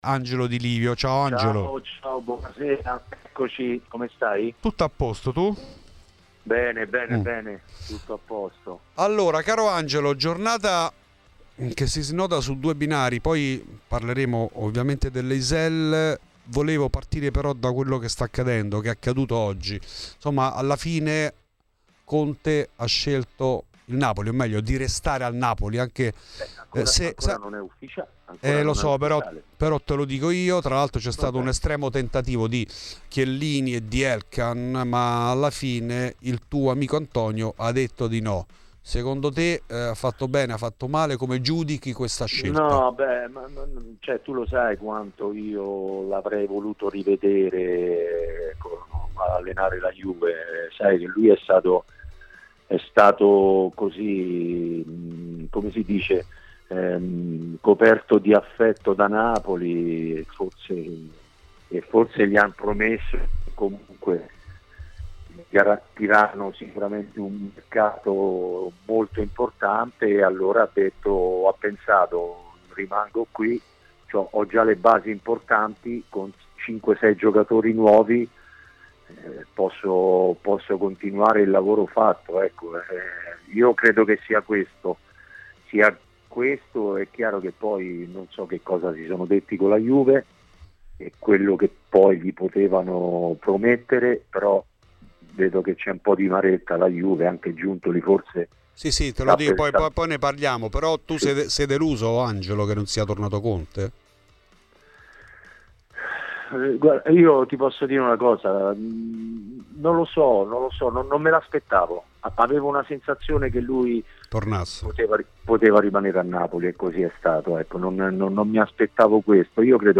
Questi ed altri argomenti sono stati trattati in ESCLUSIVA a Fuori di Juve da Angelo Di Livio. L'ex giocatore bianconero ha inoltre criticato Buffon per le sue parole contro chi tiferà Psg e non Inter sabato nella finale di Champions League.